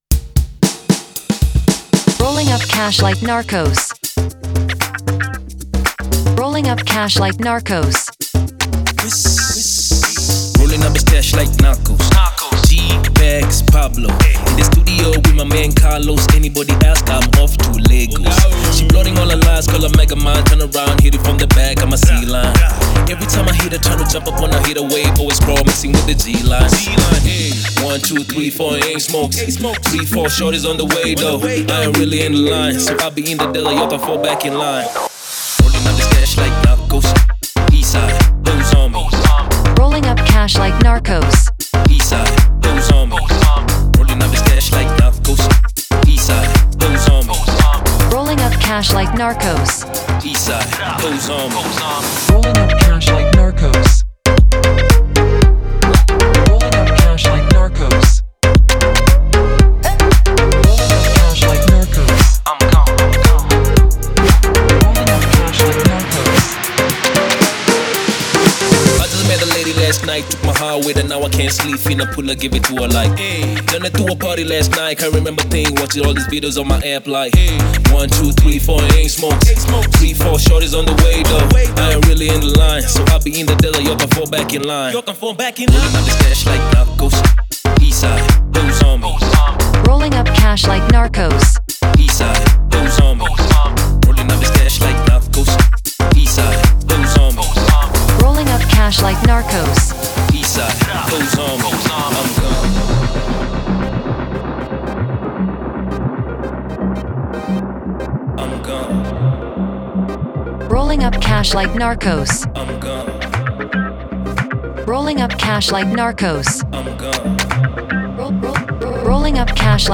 это трек в жанре хип-хоп